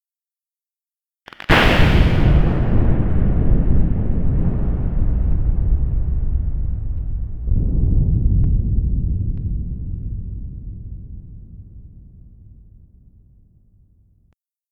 Explosion / Mega thunder
Category 🌿 Nature
explosion field-recording flash horror nature rain thunder thunder-storm sound effect free sound royalty free Nature